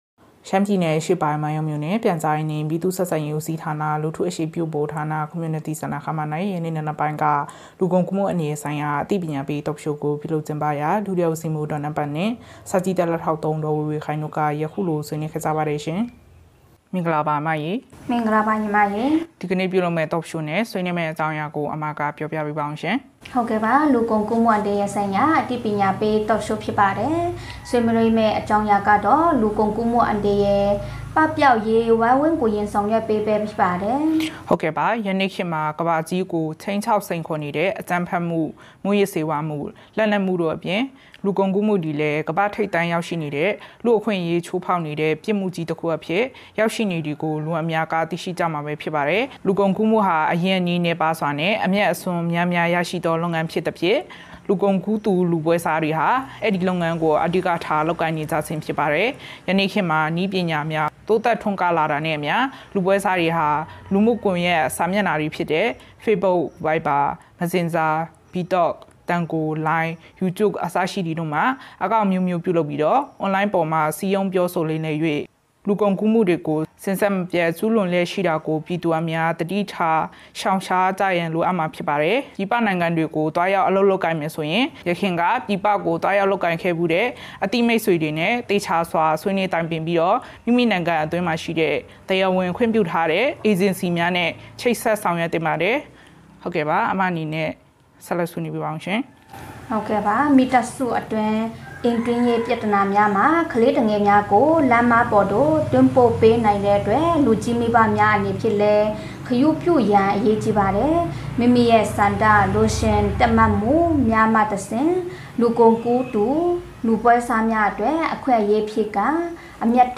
မိုင်းယောင်းမြို့၌ လူကုန်ကူးမှုအန္တရာယ်ဆိုင်ရာ အသိပညာပေး Talk Show ကျင်းပ မိုင်းယောင်း သြဂုတ် ၄